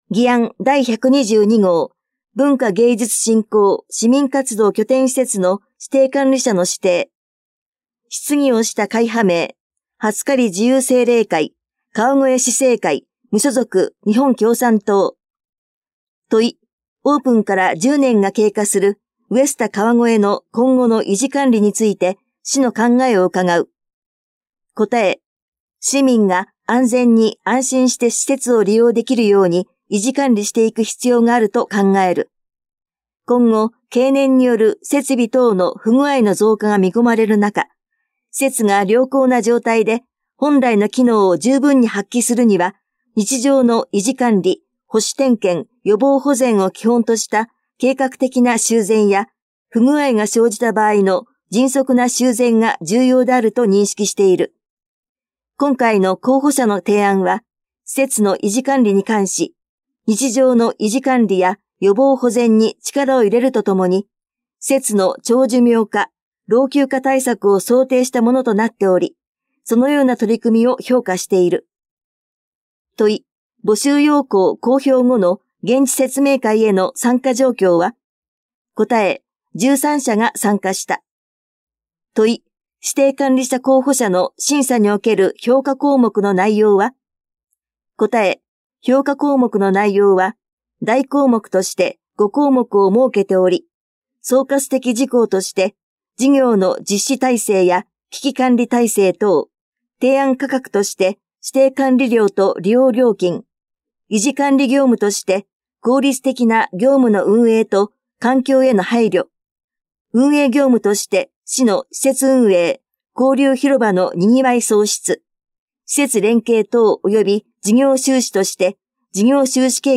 点字版・音声版かわごえ議会だより
かわごえ議会だよりの発行号ごとに、原則として内容の全文を点訳・音訳しています。